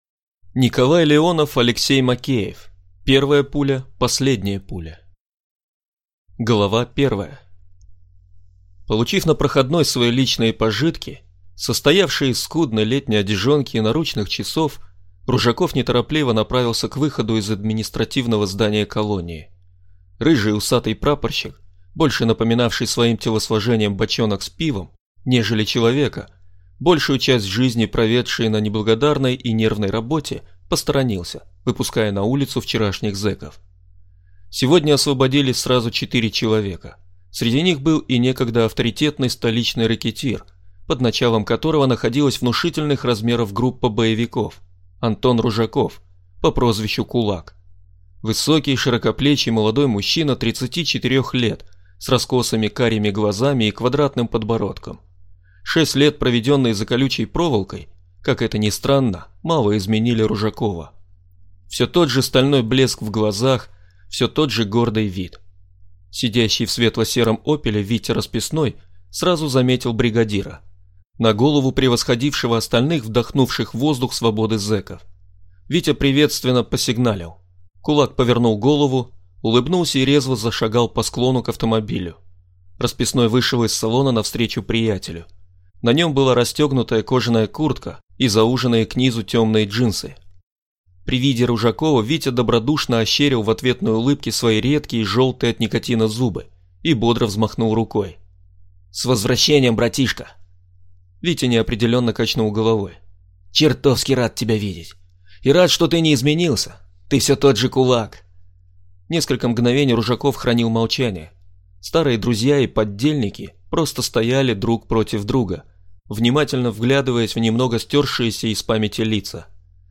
Аудиокнига Первая пуля – последняя пуля | Библиотека аудиокниг